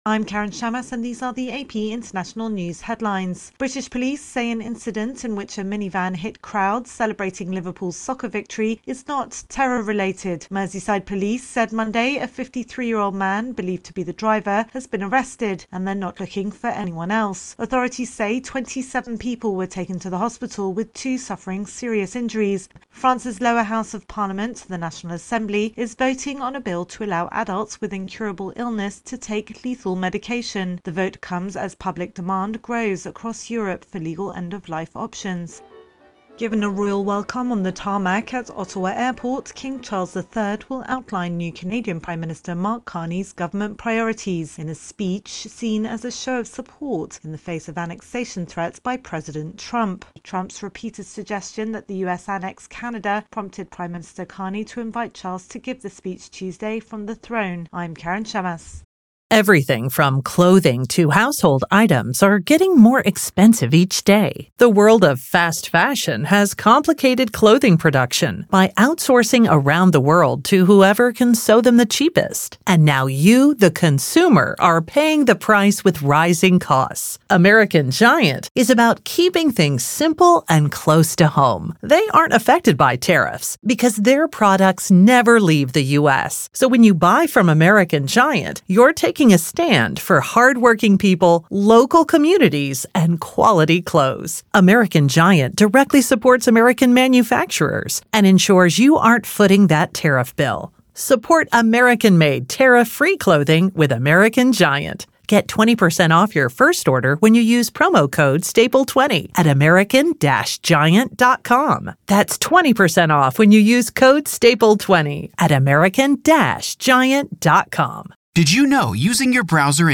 The latest international headlines